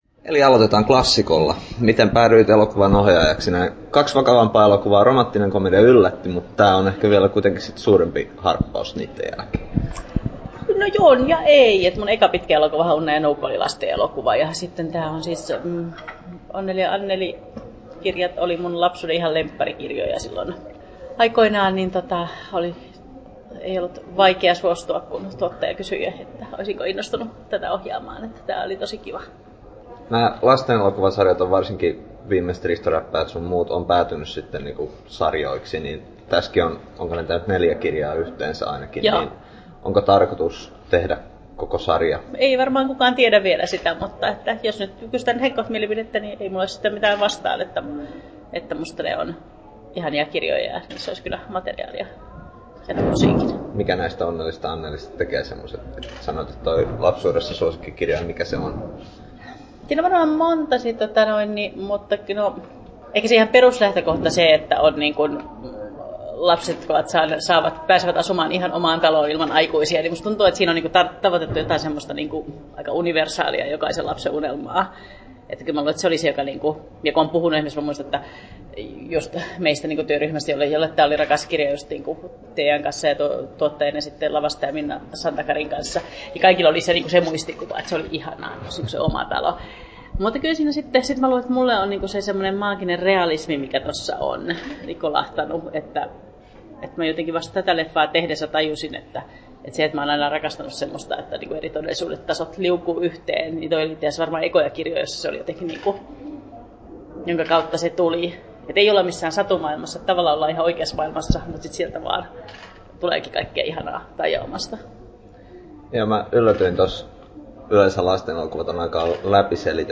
Haastattelut
10'01" Tallennettu: 13.1.2014, Turku Toimittaja